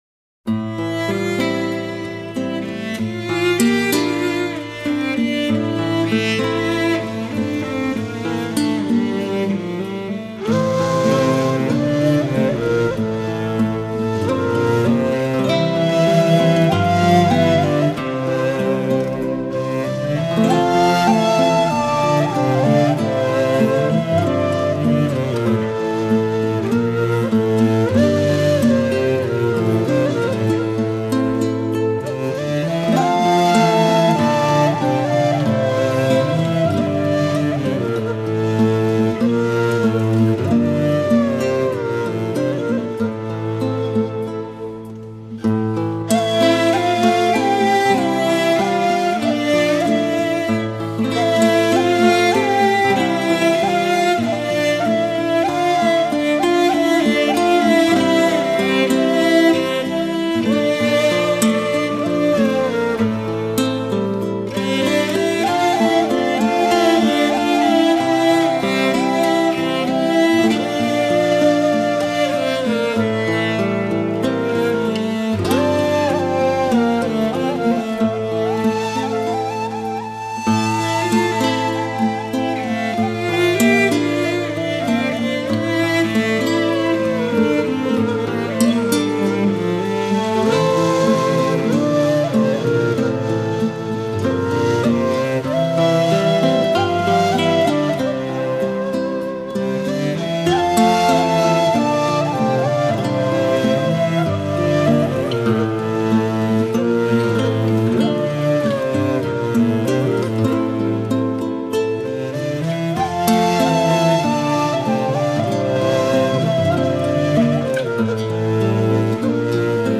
دانلود آهنگ بی کلام غمگین Finding the Remains